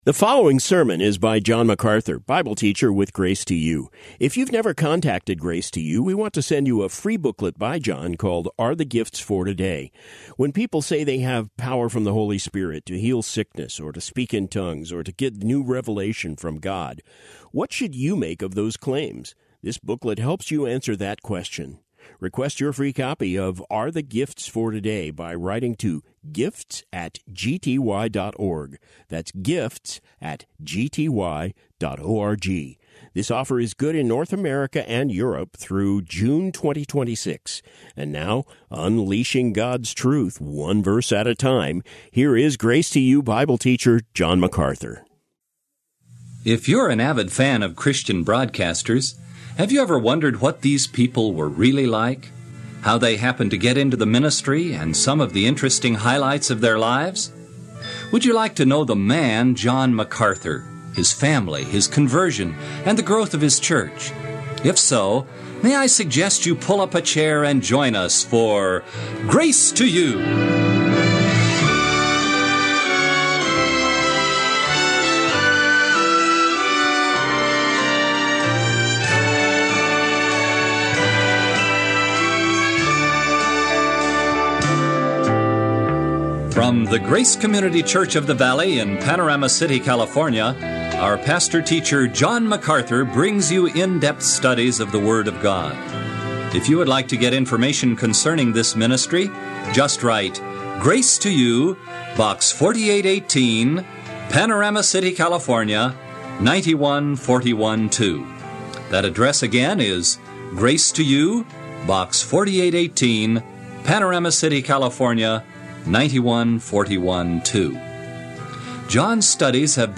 Personal Interview with John MacArthur